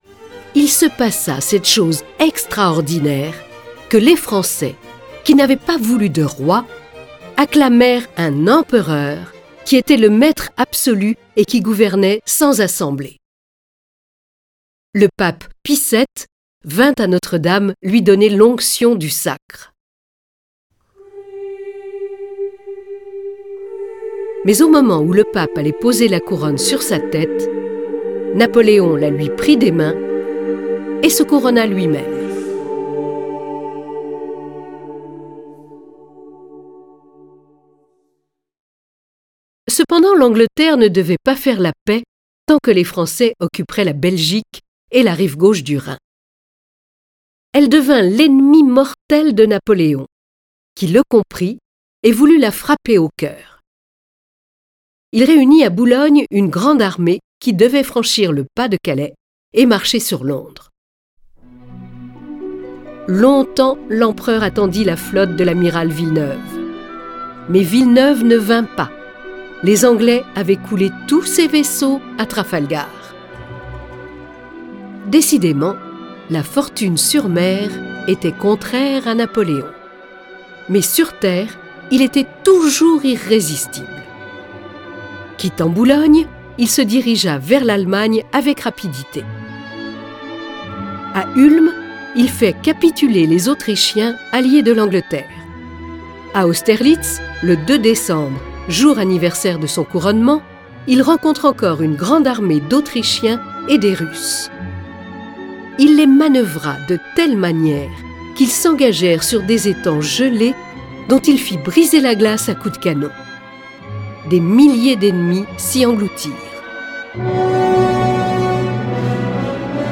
Cette version sonore de l’œuvre de Bainville est animée par sept voix, accompagnée de plus de quarante morceaux de musique classique et d’un grand nombre de bruitages.